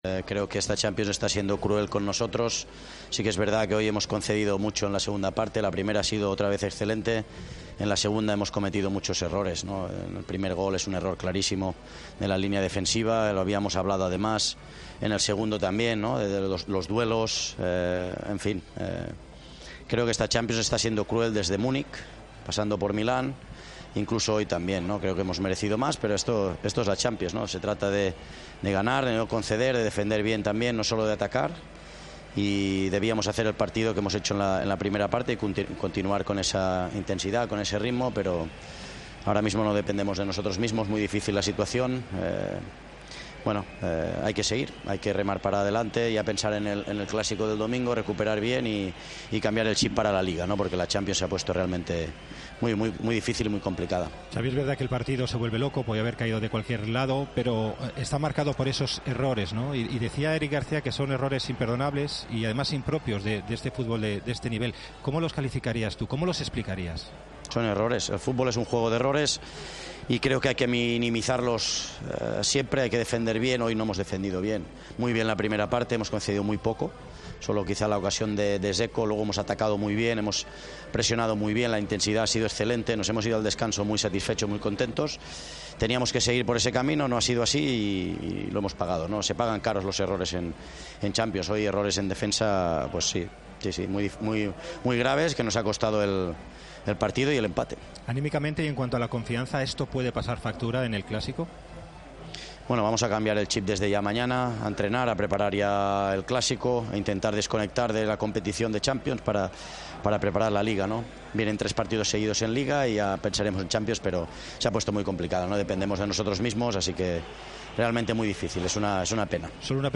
El entrenador del Barcelona analizó en Movistar y en rueda de prensa el empate de los azulgranas que deja al equipo prácticamente eliminado de la...